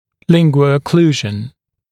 [ˌlɪŋgwəu-ə’kluːʒn][ˌлингуоу-э’клу:жн]лингвоокклюзия